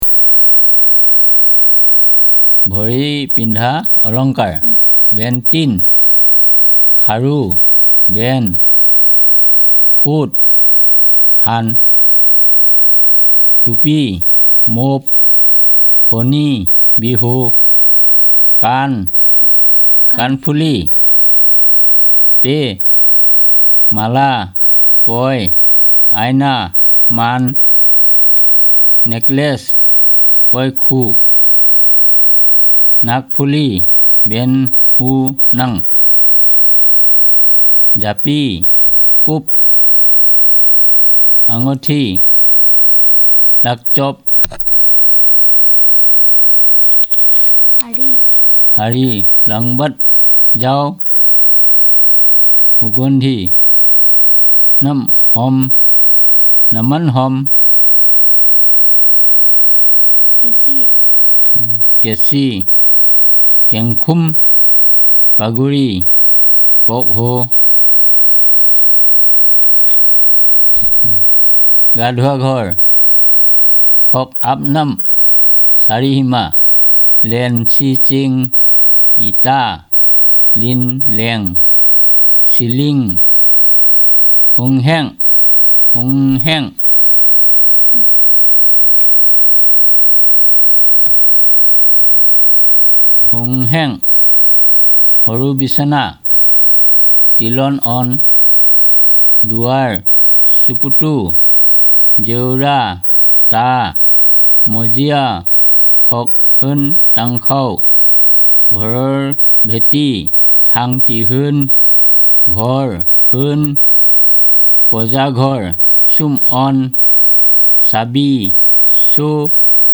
Elicitation of adorments and costumes, housing and related, fruit and seeds, food and related, trees